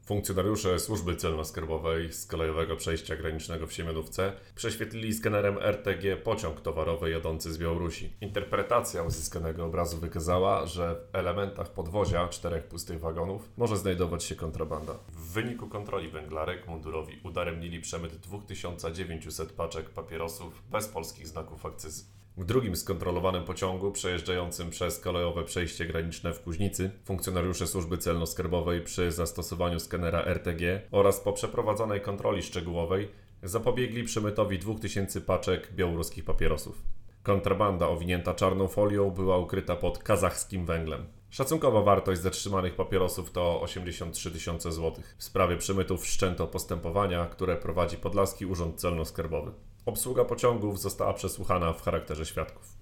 Białoruskie papierosy w wagonach kolejowych (wypowiedź mł. rew.